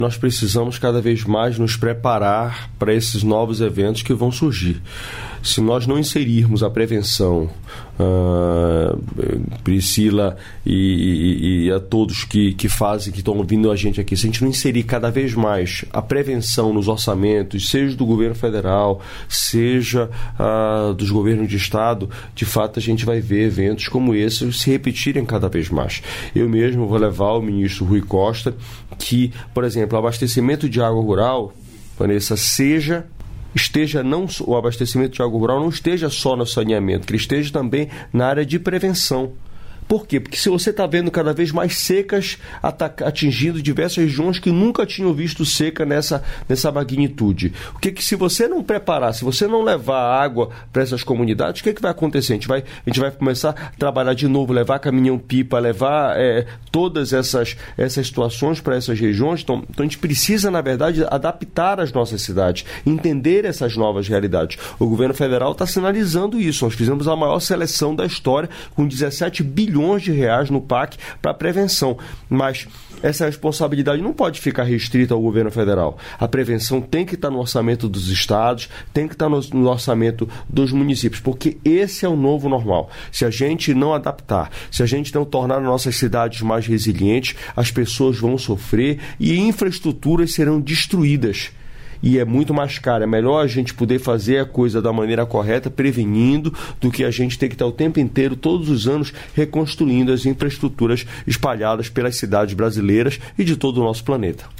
Trecho da participação do ministro das Cidades, Jader Filho, no programa Bom Dia, Ministro desta quarta-feira (04), nos estúdios da EBC, em Brasília.